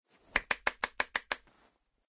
avatar_emotion_applause.ogg